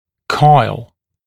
[kɔɪl][койл]виток, спираль; свертываться спиралью, кольцом